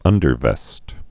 (ŭndər-vĕst)